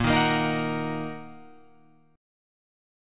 A#sus4#5 chord